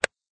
face_snap.ogg